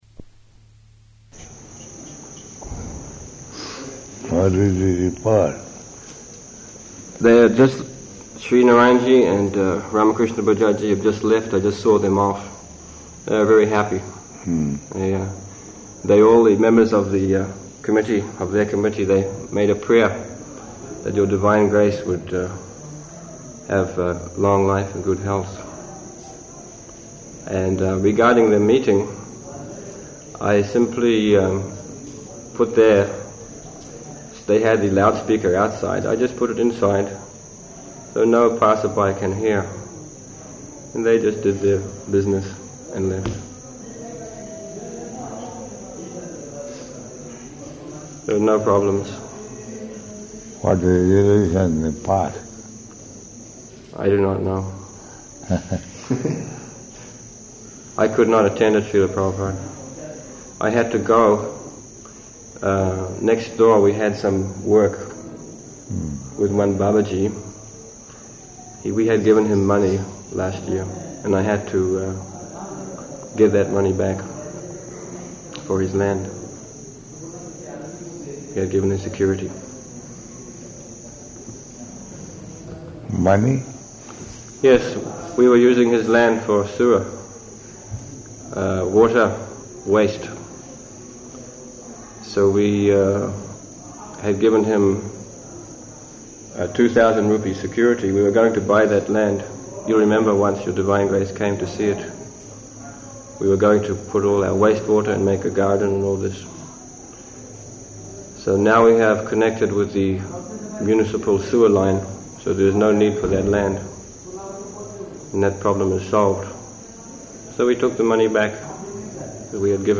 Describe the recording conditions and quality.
-- Type: Conversation Dated: November 2nd 1977 Location: Vṛndāvana Audio file